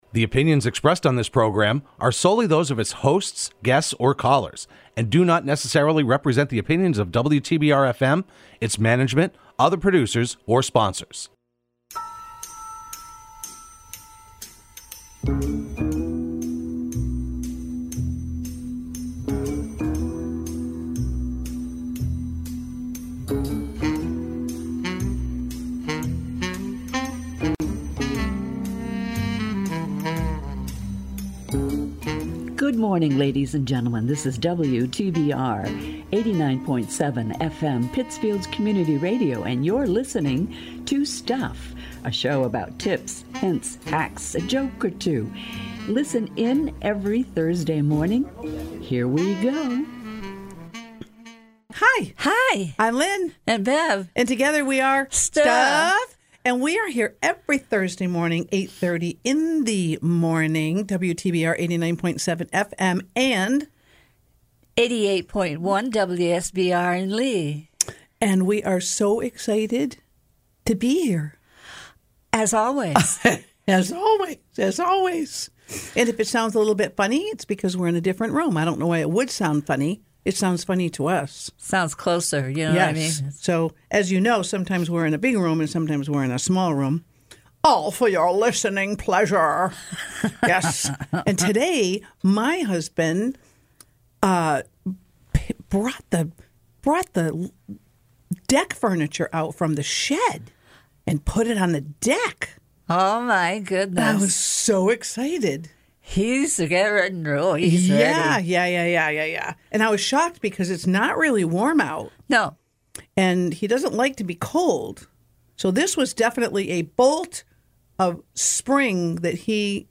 Be a guest on this podcast Language: en-us Genres: Comedy , Education Contact email: Get it Feed URL: Get it iTunes ID: Get it Get all podcast data Listen Now...